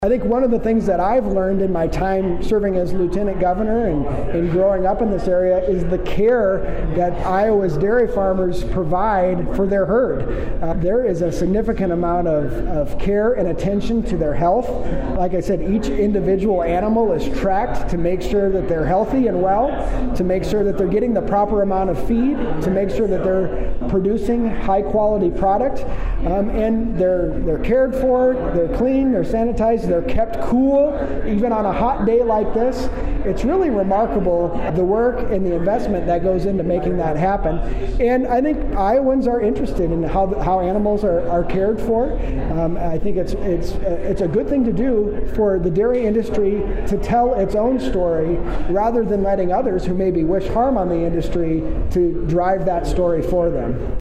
Following the reading of the proclamation, Lt. Governor Gregg answered questions from the news media, then took a tour of the dairy farm facilities.